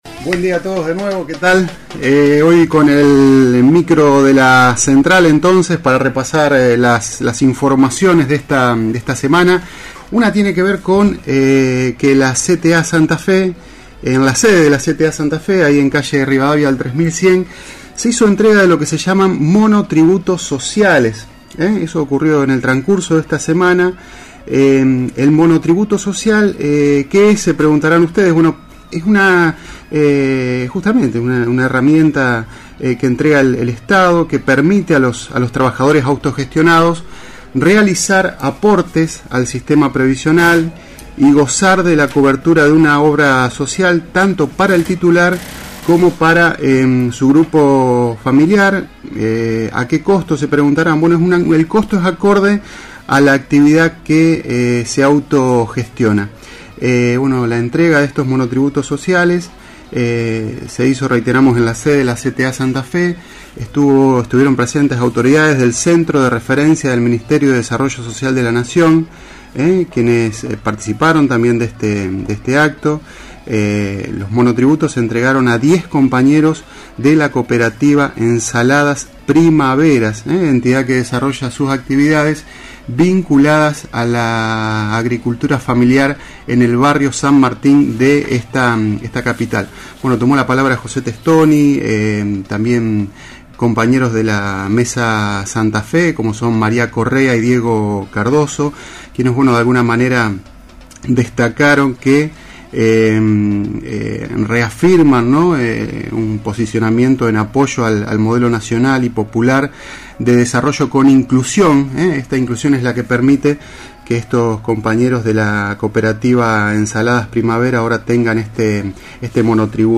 Micro Radial de la CTA Santa Fe